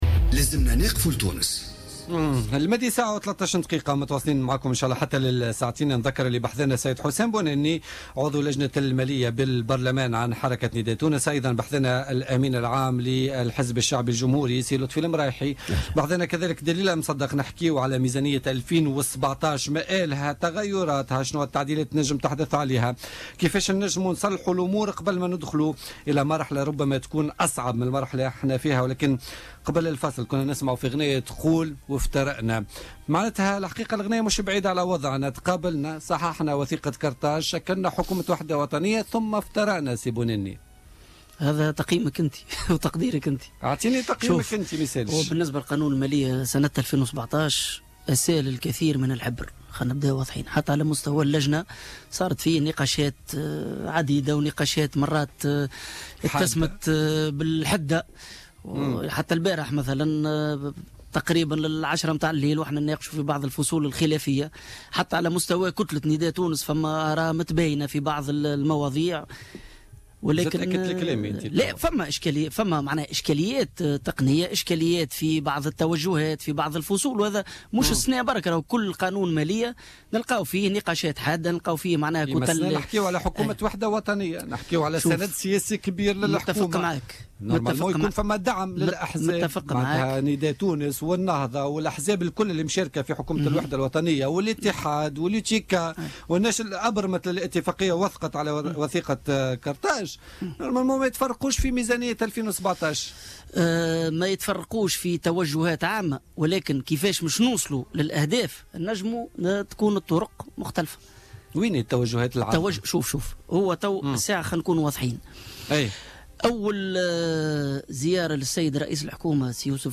أكد حسام بونني عضو لجنة المالية بمجلس نواب الشعب عن حركة نداء تونس ضيف بوليتيكا اليوم الخميس 17 نوفمبر 2016 أن يوسف الشاهد كانت له الشجاعة أول تسلمه الحكومة لمصارحة التونسيين بالوضعية الكارثية للمالية التونسية .